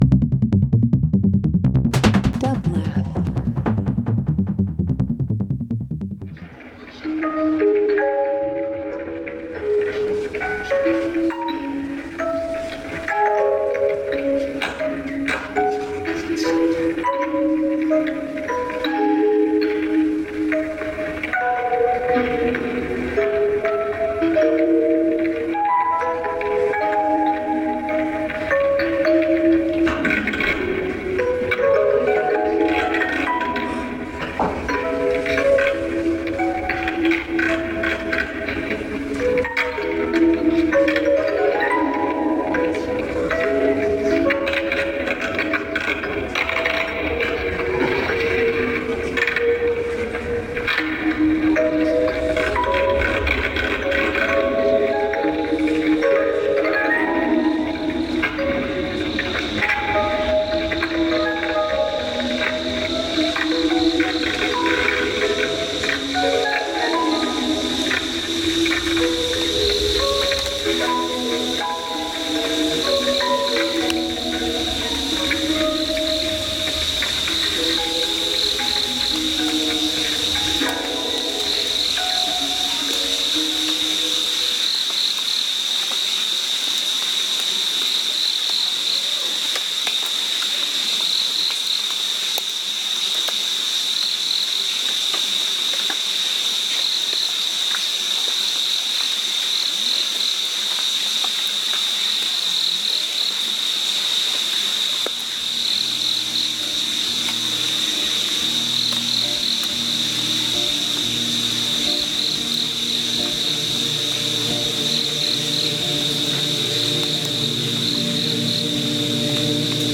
Ambient Avant-Garde Electronic Experimental Field Recording